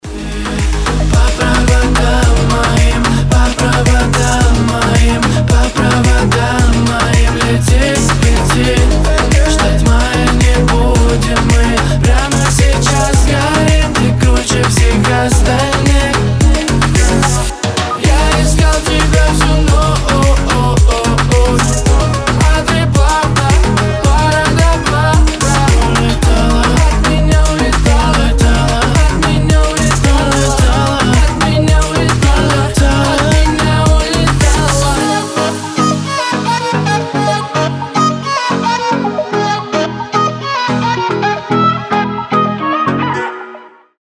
• Качество: 320, Stereo
Хип-хоп
club
чувственные
house